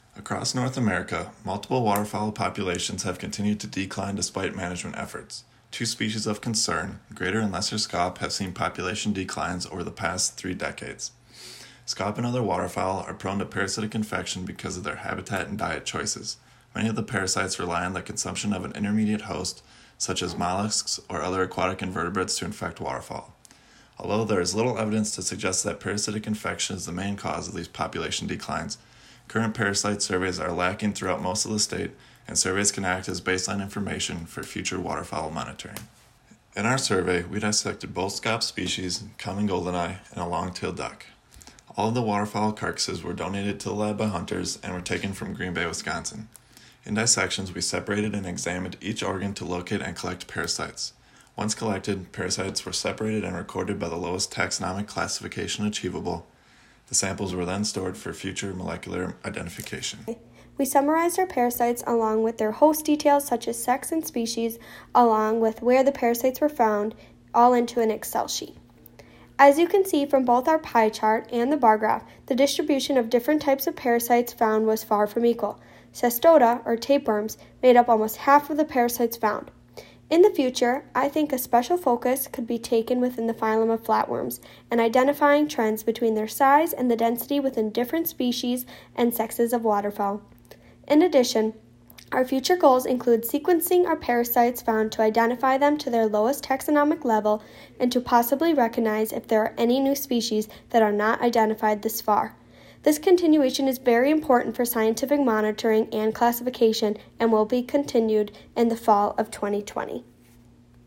Included in this article is a copy of their final project poster and a recording of the students describing their work: